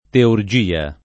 teurgia [ teur J& a ] s. f.